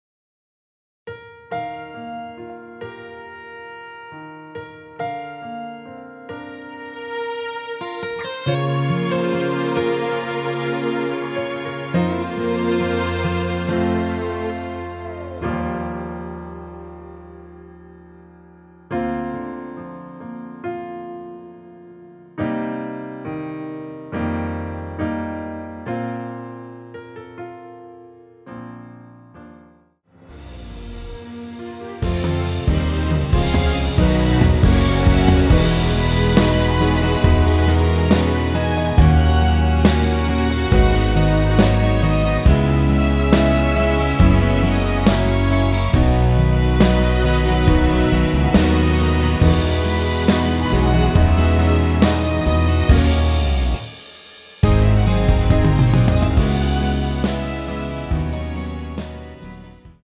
Bb
앞부분30초, 뒷부분30초씩 편집해서 올려 드리고 있습니다.
O.S.T